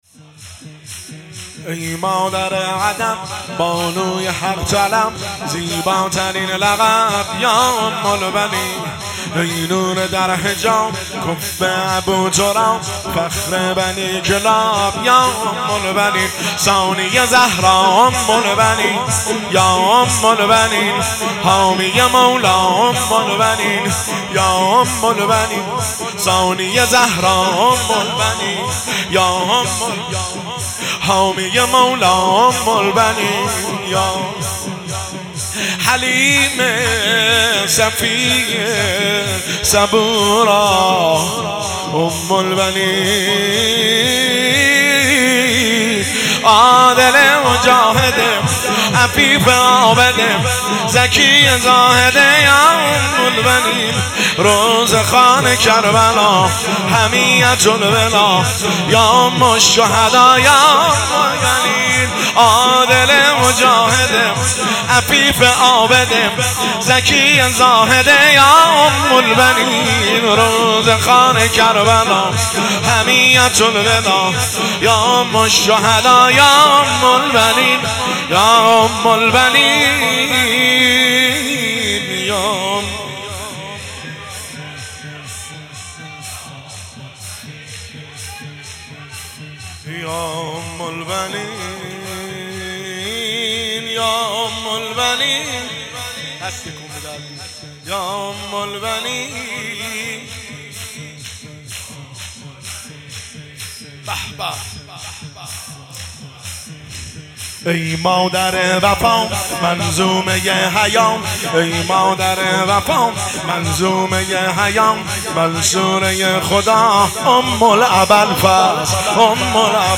شور شام وفات حضرت اُم البنین (س) 1403